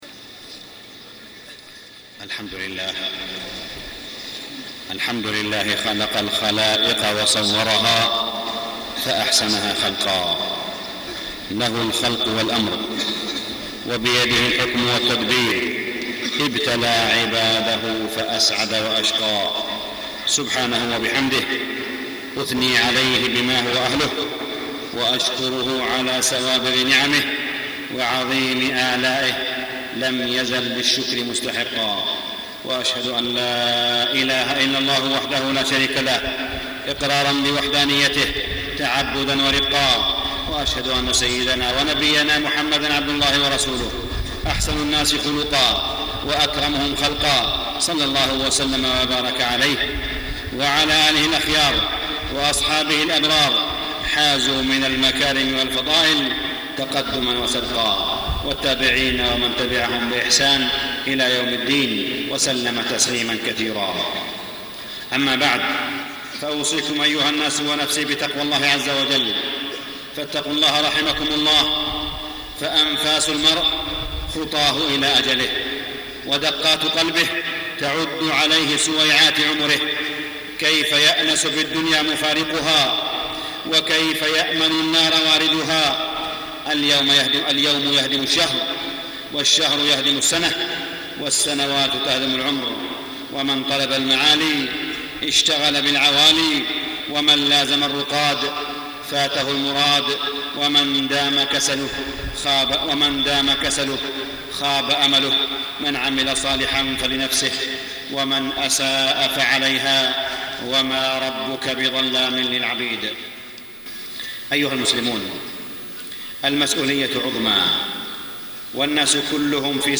تاريخ النشر ٢٤ ذو الحجة ١٤٢٥ هـ المكان: المسجد الحرام الشيخ: معالي الشيخ أ.د. صالح بن عبدالله بن حميد معالي الشيخ أ.د. صالح بن عبدالله بن حميد خرق السفينة